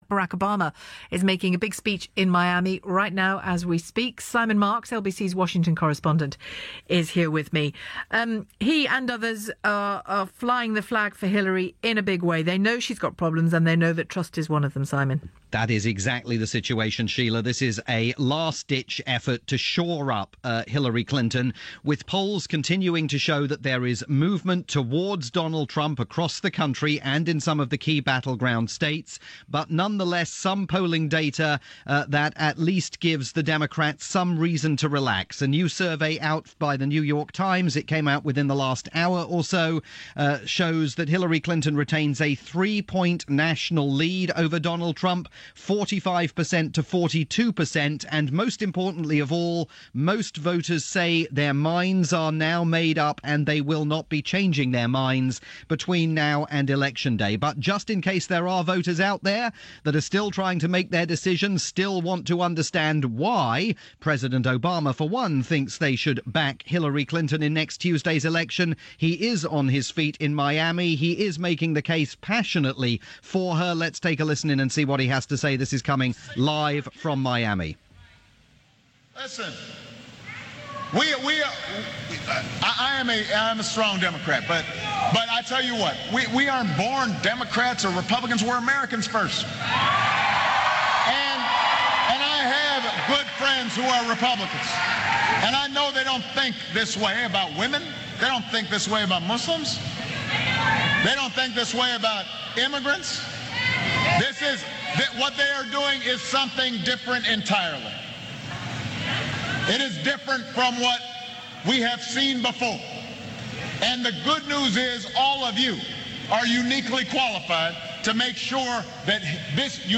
live report aired on Shelagh Fogarty's program on the UK's LBC Radio.